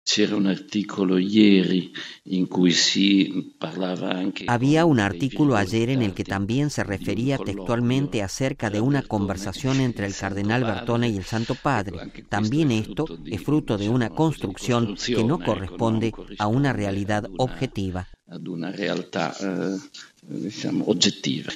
El P. Lombardi responde a los periodistas